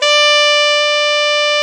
SAX A.MF D0H.wav